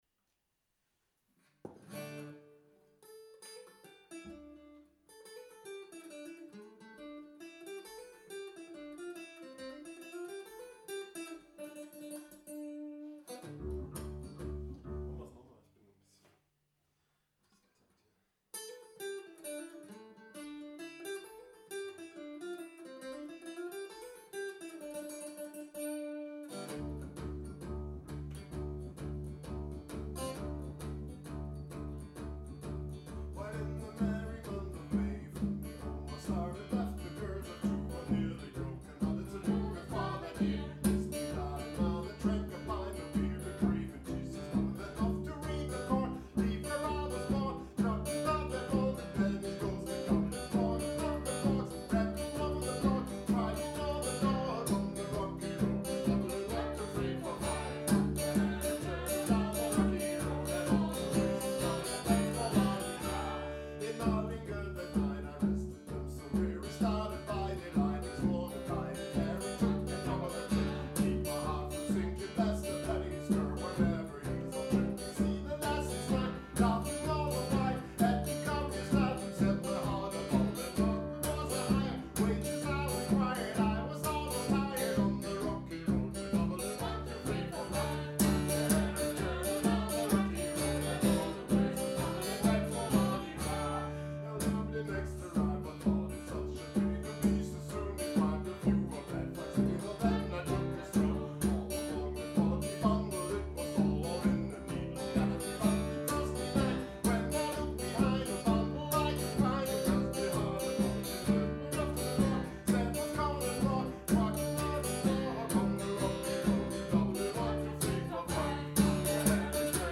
Genre: Folk.